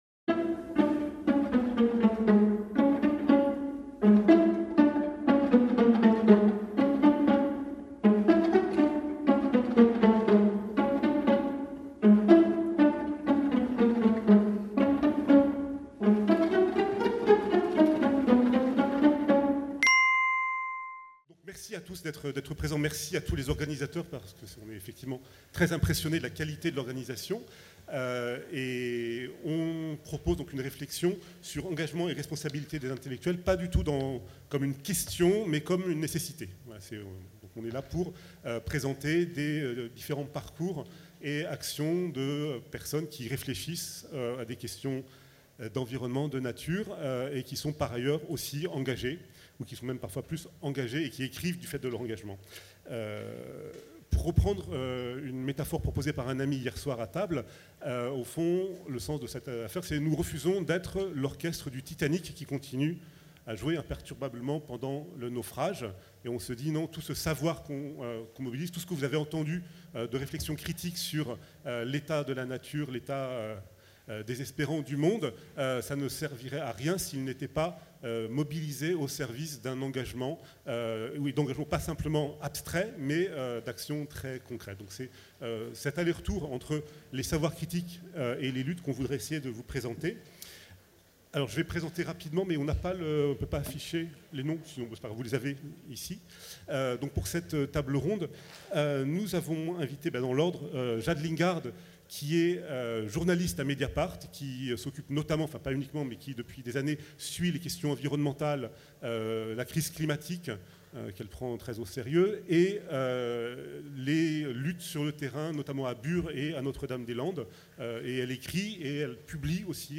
Responsabilité et engagement face au désastre - Festival ALLEZ SAVOIR | Canal U